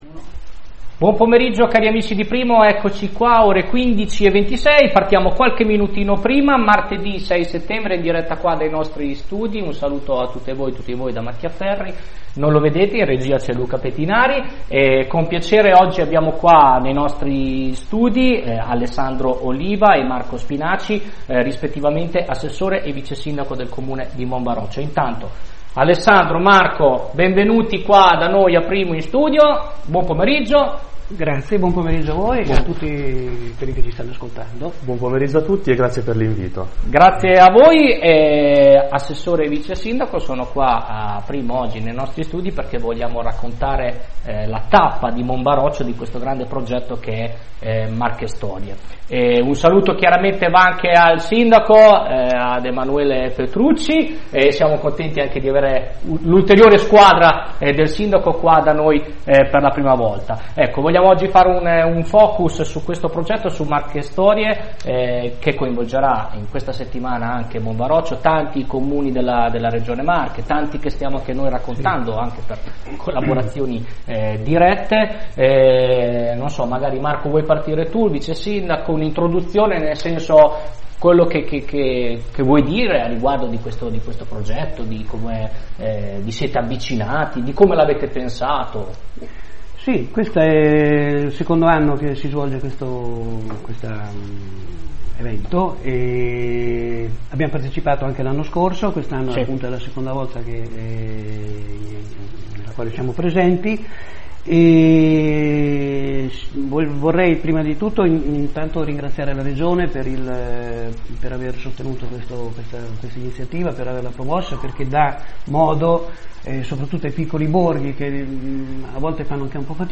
MArCHESTORIE torna a Mombaroccio: intervista
Assieme al consigliere Alessandro Oliva e al vicesindaco Marco Spinaci commentiamo l'imminente seconda edizione di MArCHESTORIE